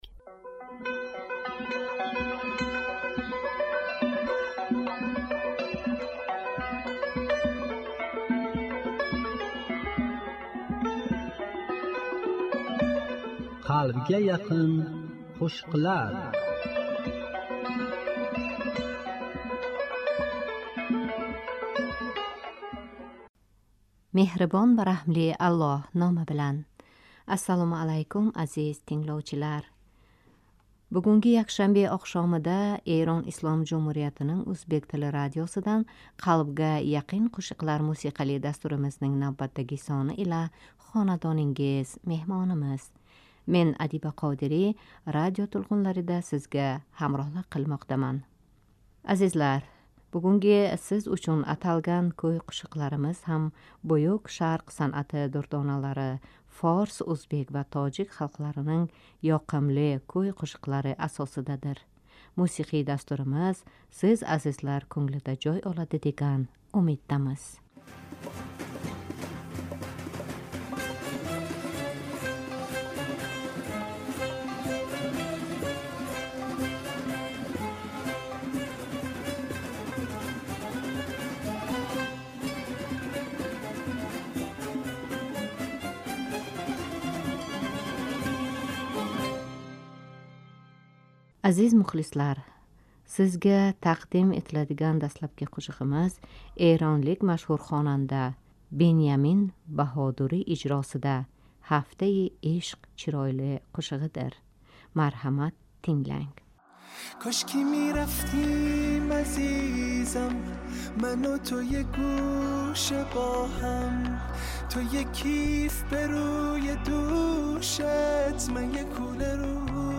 Форс ўзбек, ва тожик халқларининг ёқимли куй-қўшиқлар.